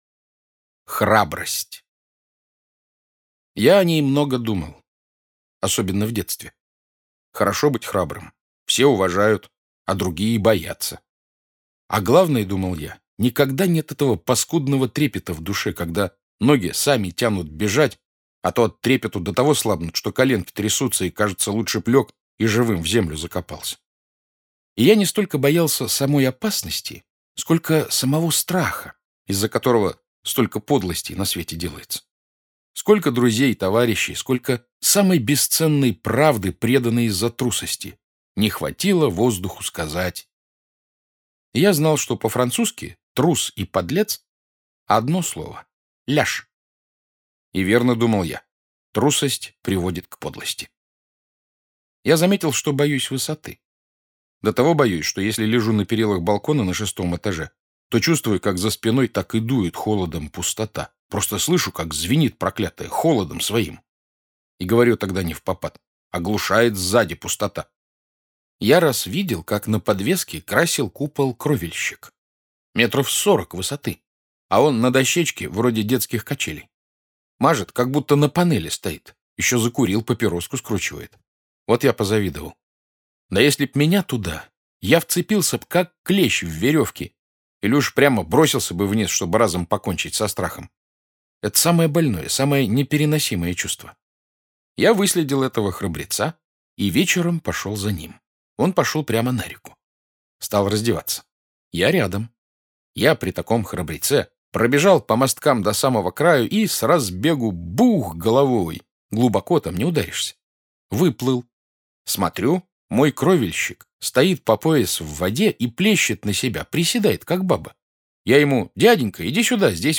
Храбрость - аудио рассказ Бориса Житкова - слушать онлайн